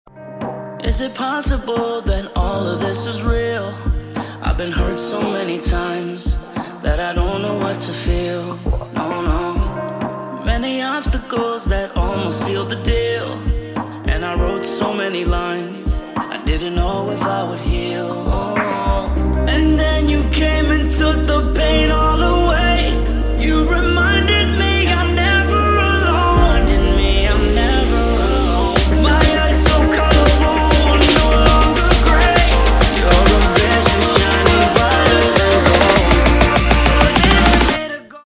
• Качество: 224, Stereo
поп
мужской вокал
dance
романтичные
Melodic
romantic
vocal